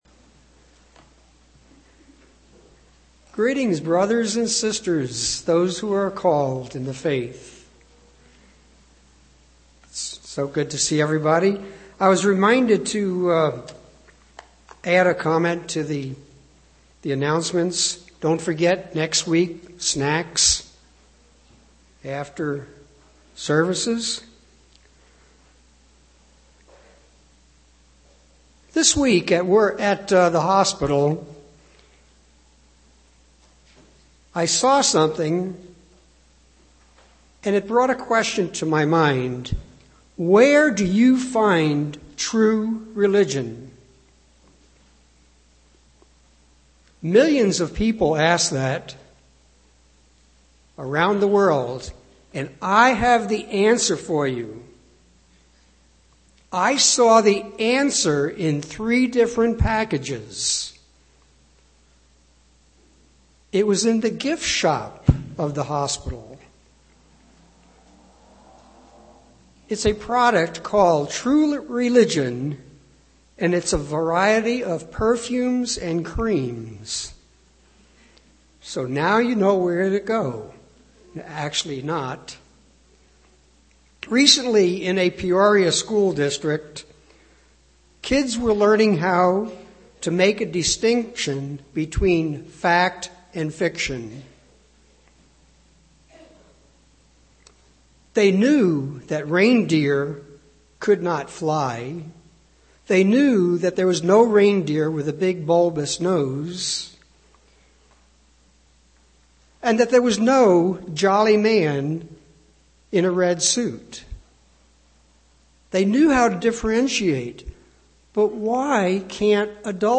UCG Sermon Notes Title: Traditions-Does God care?